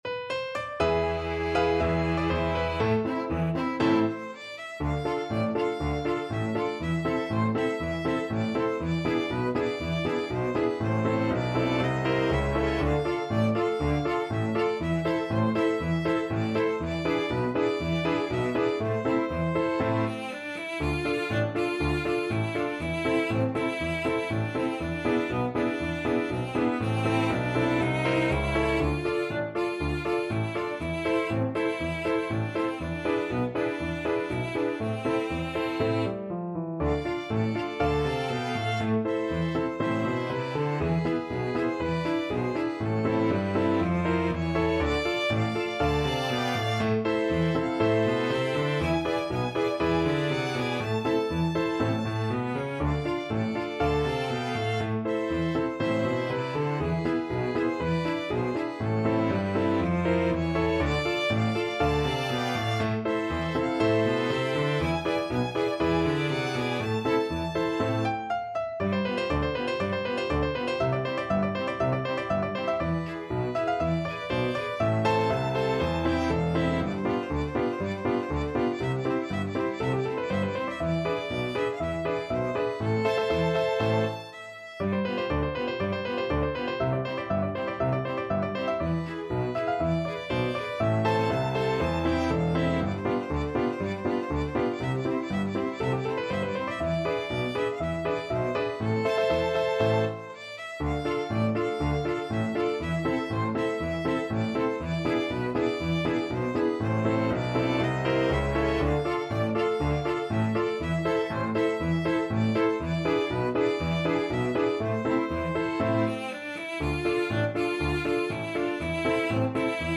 Free Sheet music for Piano Quintet
Violin 1Violin 2ViolaCelloPiano
Fast Two in a Bar =c.120
A minor (Sounding Pitch) (View more A minor Music for Piano Quintet )
2/2 (View more 2/2 Music)
Traditional (View more Traditional Piano Quintet Music)
world (View more world Piano Quintet Music)